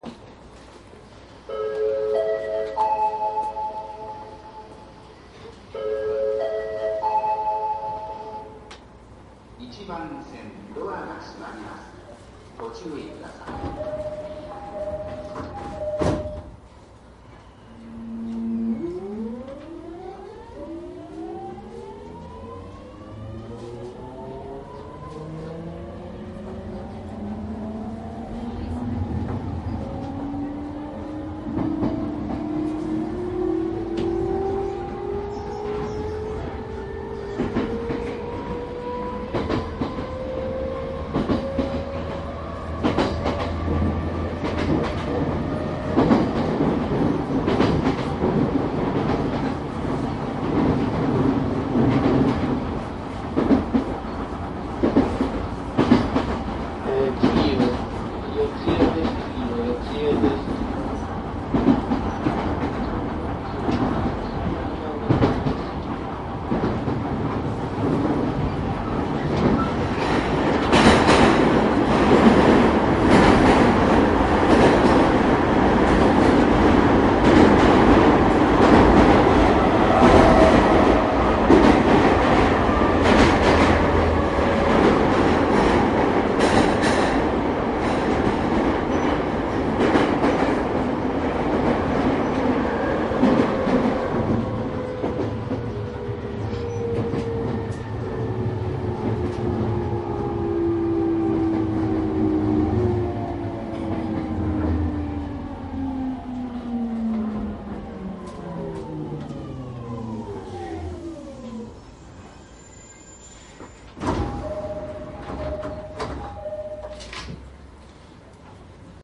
JR総武線 209系 ＞船橋方面＜ 走行音♪
夕方の船橋方面で録音しています。
機器未更新にて登場時の走行音です。
マスター音源はデジタル44.1kHz16ビット（マイクＥＣＭ959）で、これを編集ソフトでＣＤに焼いたものです。